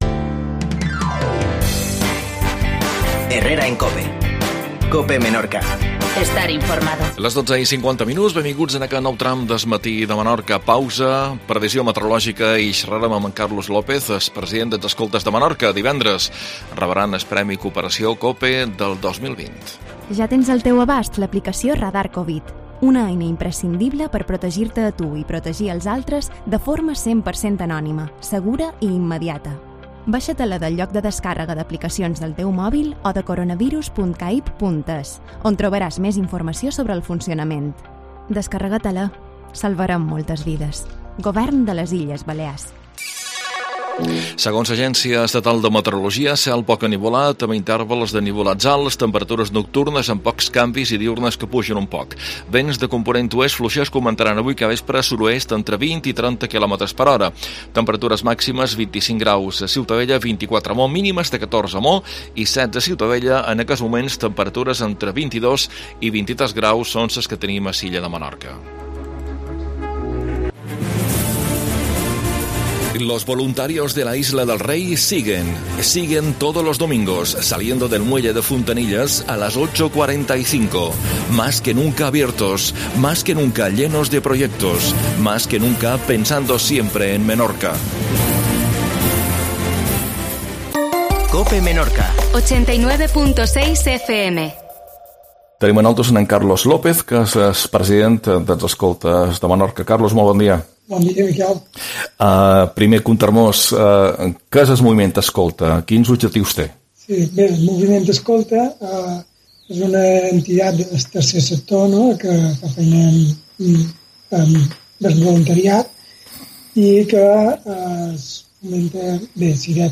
AUDIO: "Escoltes de Menorca" Premi Cooperacio 2020. Entre4vista